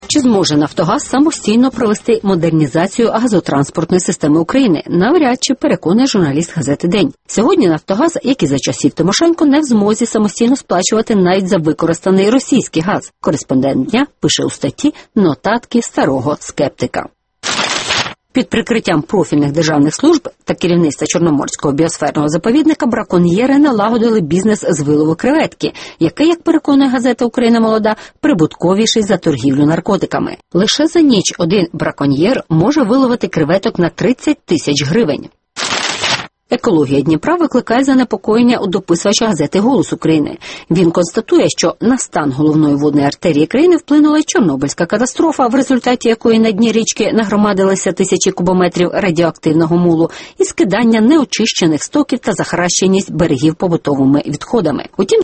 Екологія Дніпра під загрозою (огляд преси)